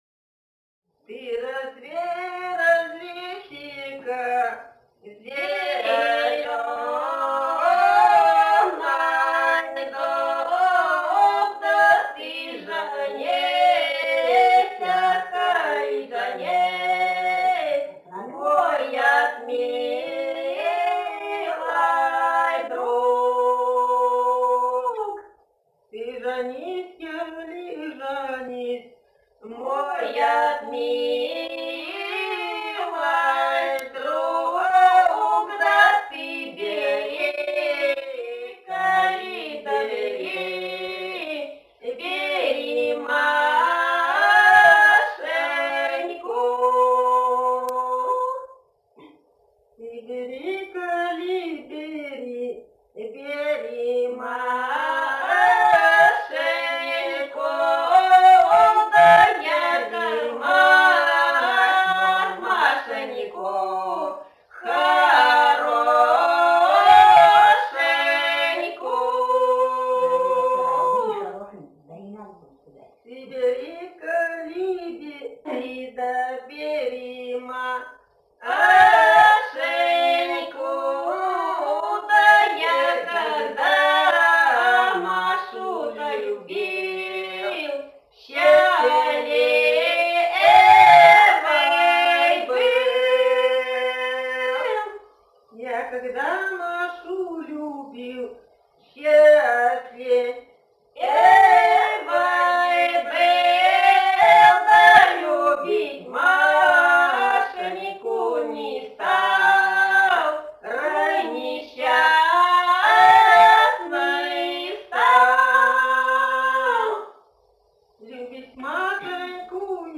с. Белое Катон-Карагайского р-на Восточно-Казахстанской обл.